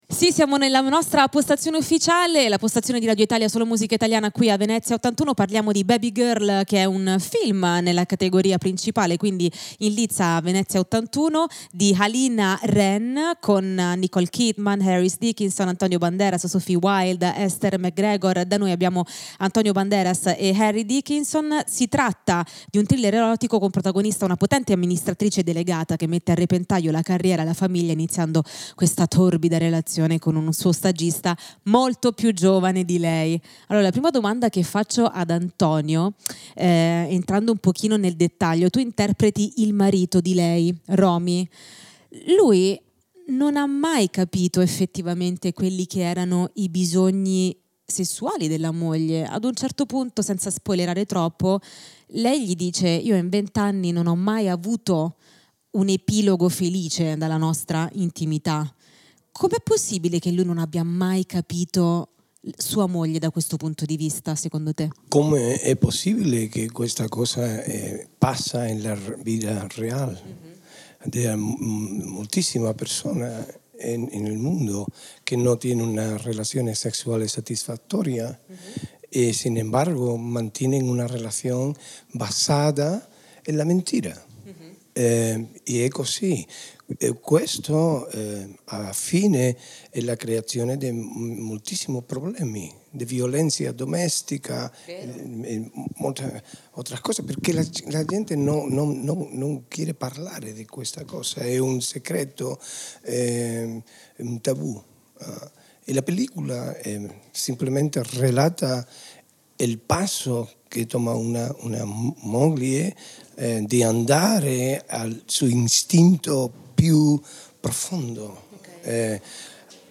Radio Italia solomusicaitaliana è radio ufficiale dell'81esima Mostra Internazionale d'Arte Cinematografica - La Biennale di Venezia
Intervista a Antonio Banderas e Harris Dickinson (Babygirl) del 30/08/2024